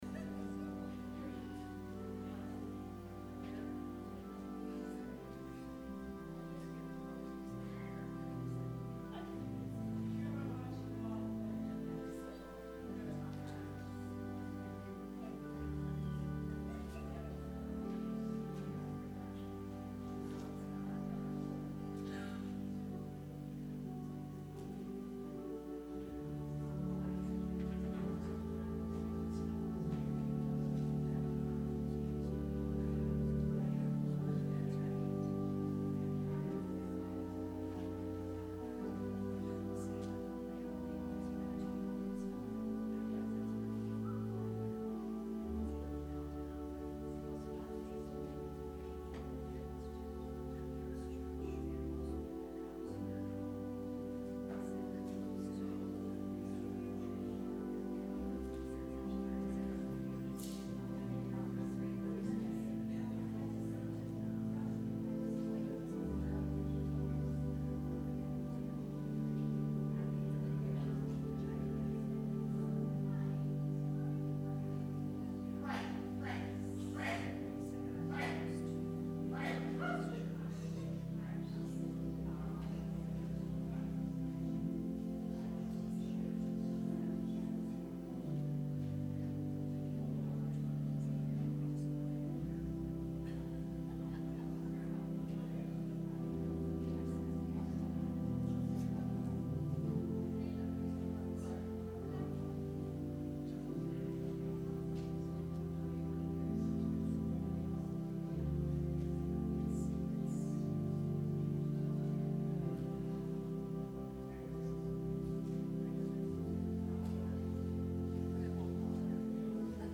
Sermon – April 21, 2019